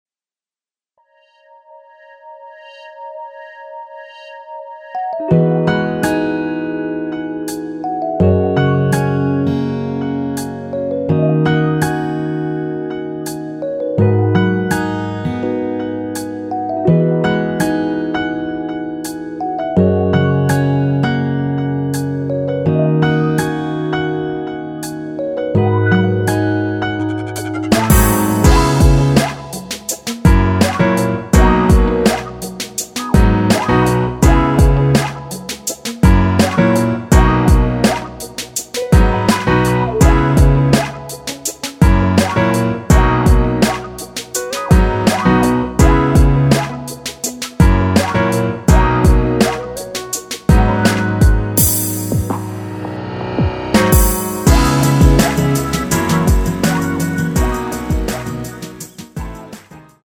노래방에서 음정올림 내림 누른 숫자와 같습니다.
앞부분30초, 뒷부분30초씩 편집해서 올려 드리고 있습니다.
중간에 음이 끈어지고 다시 나오는 이유는